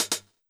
CLF Closed Hat 1.wav